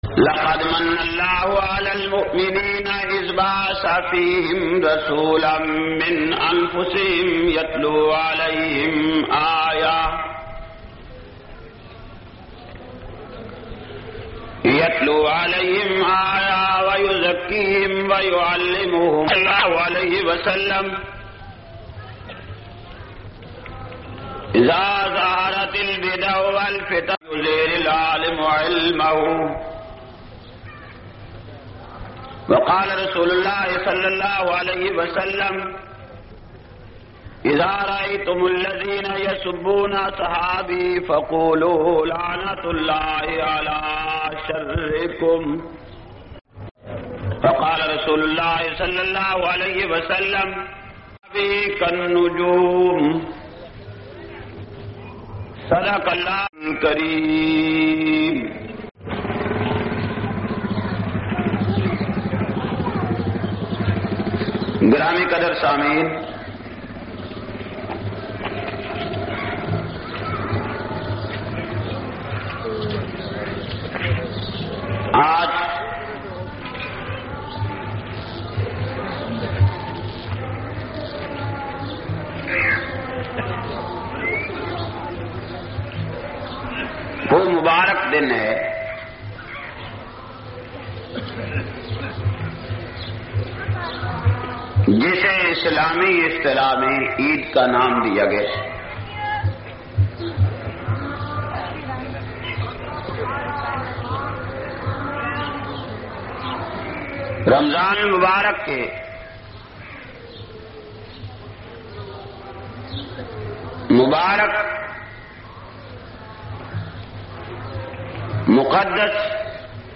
299- Khutba Eid Ul Fiter-Jhang.mp3